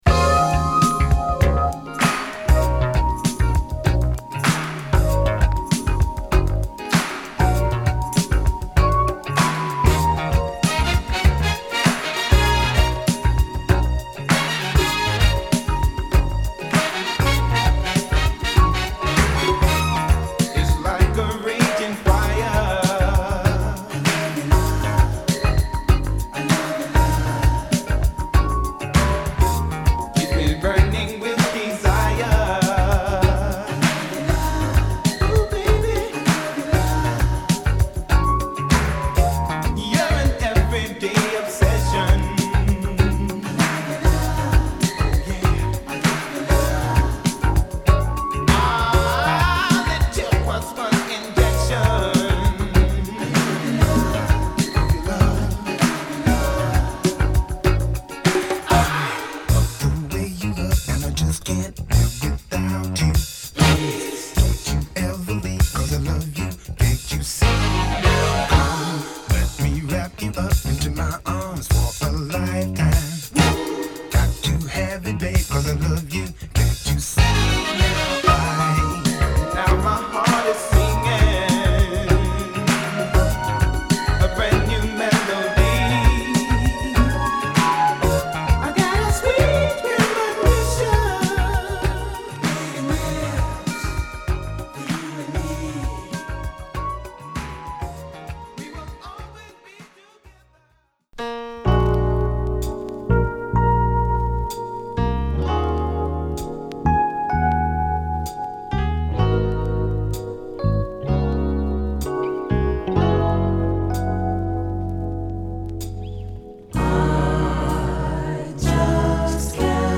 メロウ・フュージョン〜ソウル・ブギー視点の好作を残す
アーバンなブギーソウルA